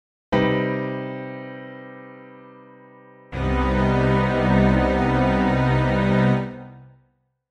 C minor, major7
Click to hear a Cm(maj7) Chord.
c_minor_major7_chord.mp3